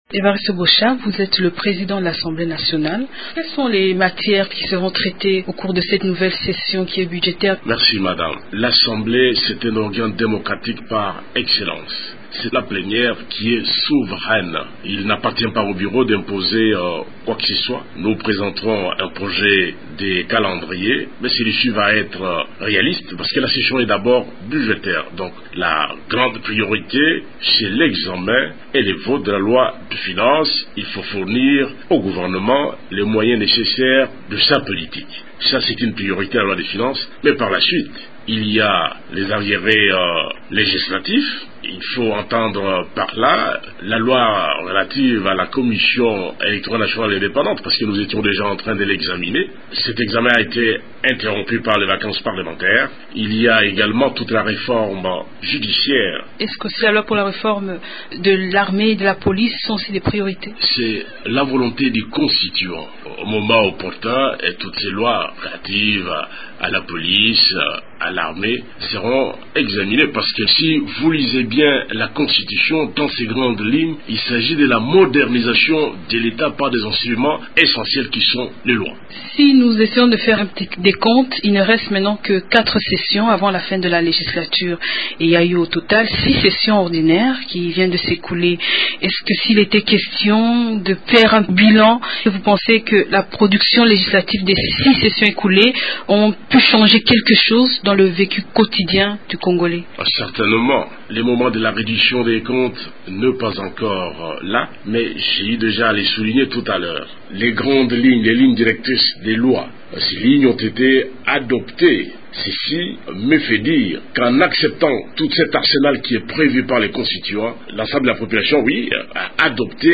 La rentrée parlementaire c’est ce mardi au Palais du Peuple. A ce sujet, notre invité ce matin c’est Evariste Boshab, président de l’assemblée nationale.